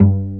Pizz(2)_G2_22k.wav